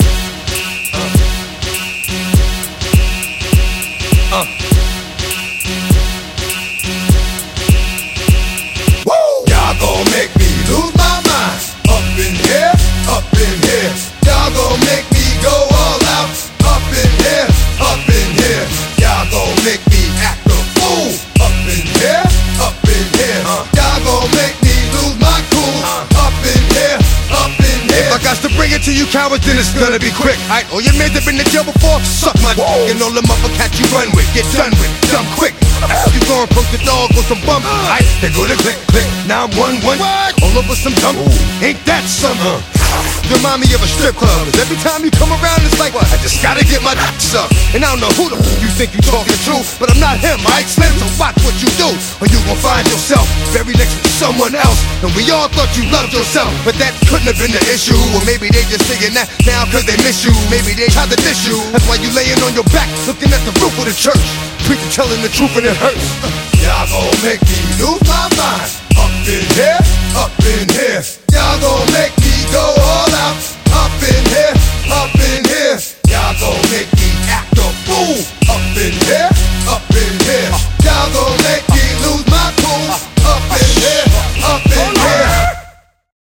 BPM101-202
Audio QualityPerfect (High Quality)